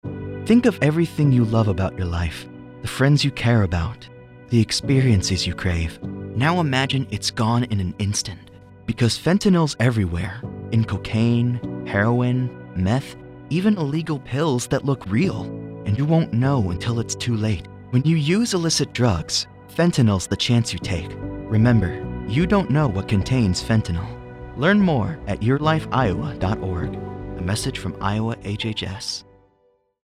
Radio spot :30 Radio Spot | Fentanyl | 18-30 Male Most people have heard of fentanyl, but they may not understand how dangerous of a threat it poses to them.